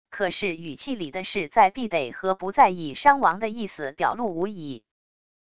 可是语气里的势在必得和不在意伤亡的意思表露无遗wav音频生成系统WAV Audio Player